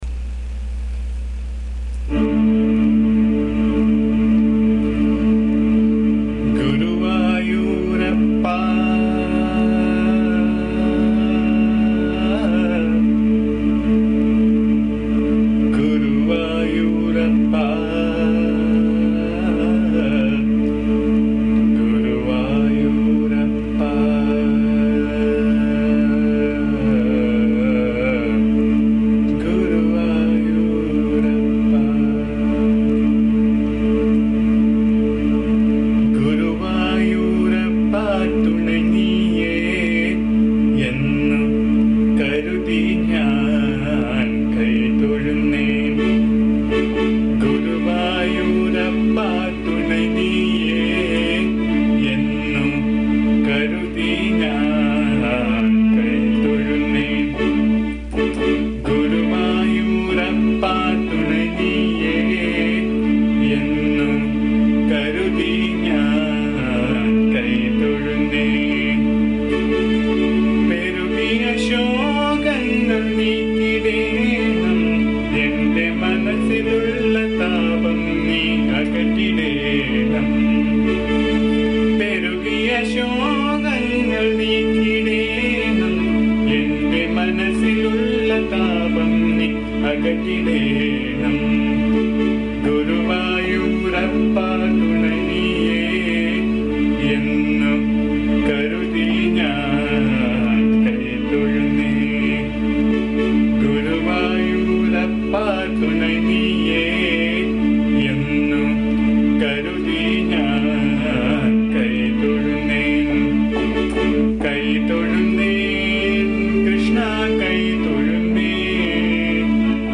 This is a beautiful song set in Mohanam Raga and praising Krishna who is present at Guruvayoor. The song has been recorded in my voice which can be found here. Please bear the noise, disturbance and awful singing as am not a singer.
AMMA's bhajan song